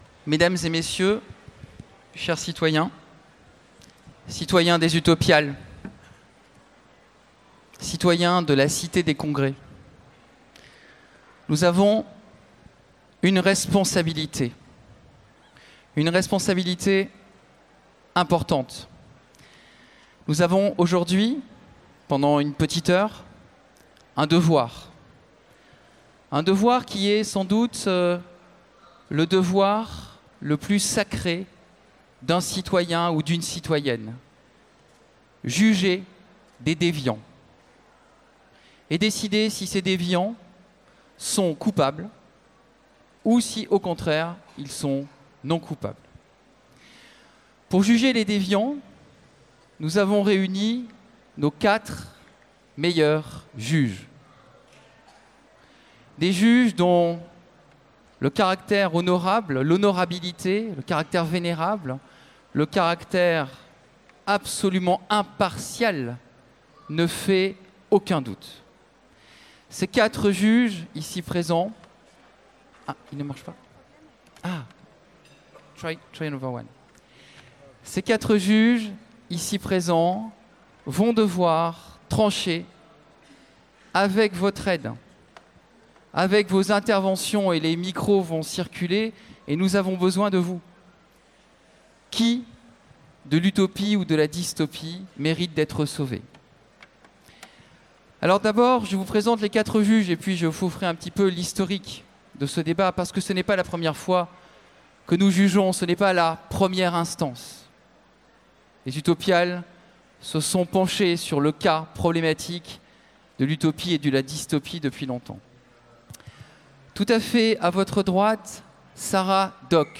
Utopiales 2017 : Conférence Utopie vs Dystopie